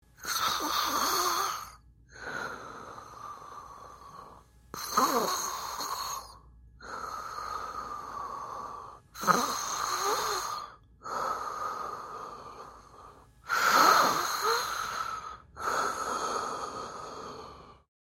Звуки спящего человека
На этой странице собраны звуки спящего человека – от едва слышного дыхания до мягкого шевеления под одеялом.
Звук крепкого сна: Тихое дыхание, спокойные сны